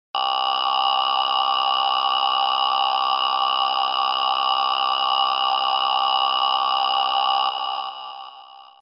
一応再生してみると「ア〜〜〜〜」と長々と言ってると思います。
単調な歌声（MP3）